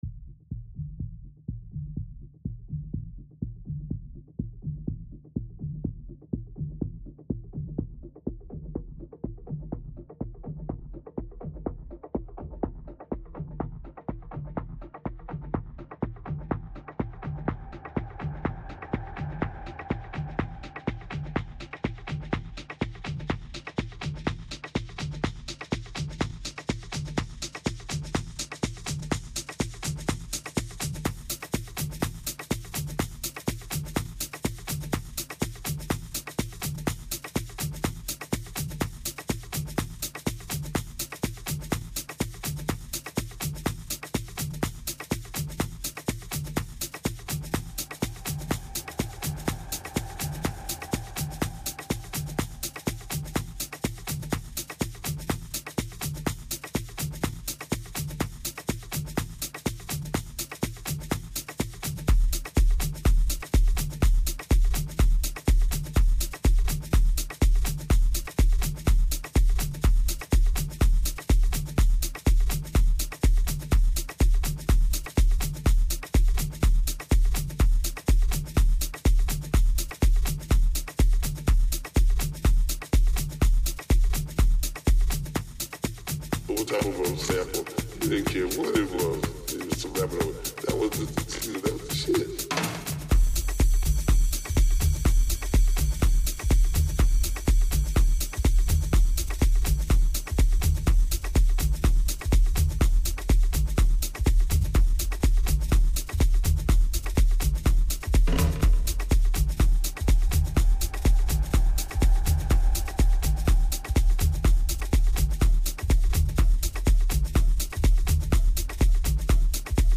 Online House, Tribal, Tech House, Trance and D&B.